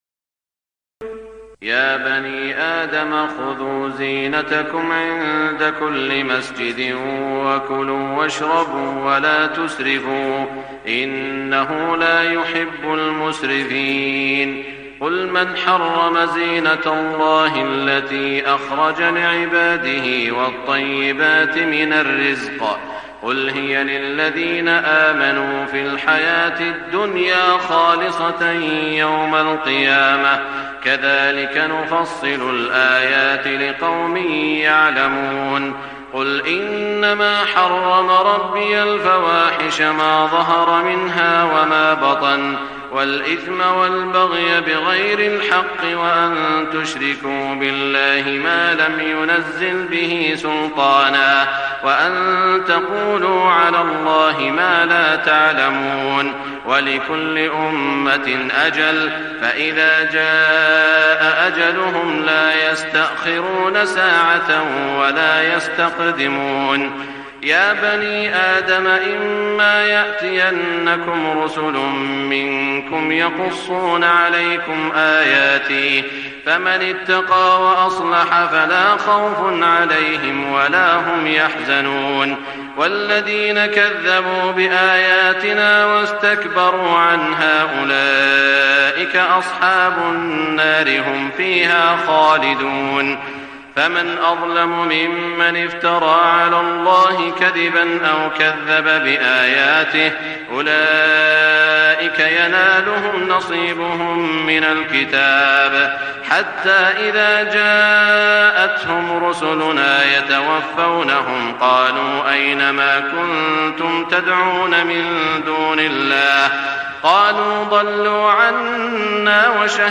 تهجد ليلة 28 رمضان 1422هـ من سورة الأعراف (31-93) Tahajjud 28 st night Ramadan 1422H from Surah Al-A’raf > تراويح الحرم المكي عام 1422 🕋 > التراويح - تلاوات الحرمين